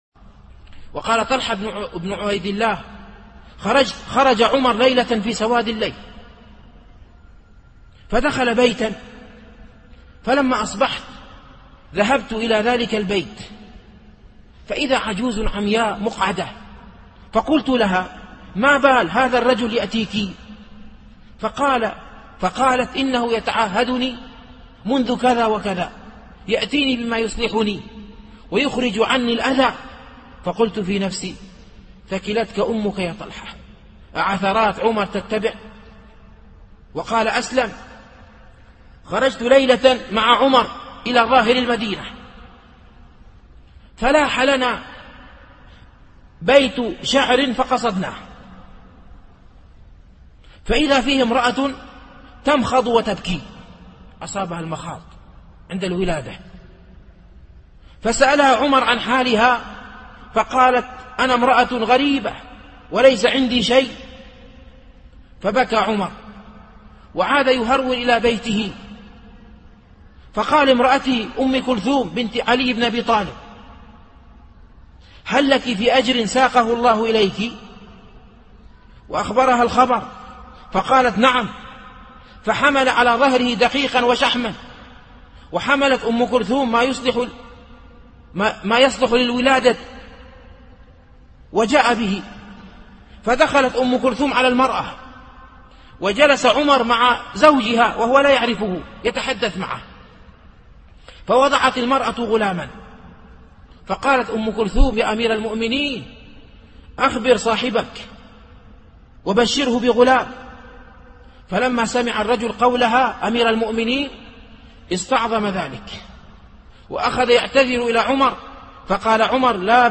التنسيق: MP3 Mono 22kHz 32Kbps (VBR)